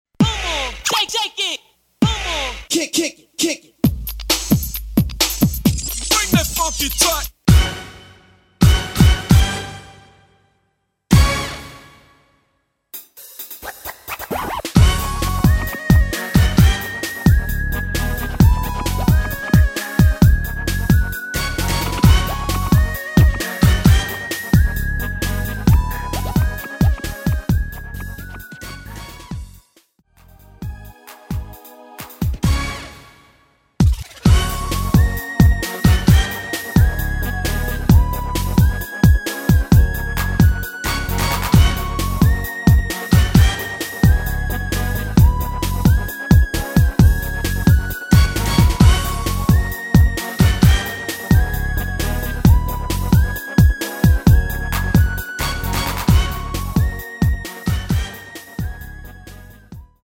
간주와 후주가 너무 길어서 라이브에 사용하시기 좋게 짧게 편곡 하였습니다.
F#
앞부분30초, 뒷부분30초씩 편집해서 올려 드리고 있습니다.